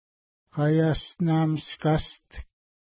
Pronunciation: ka:ja:stna:miska:st
Pronunciation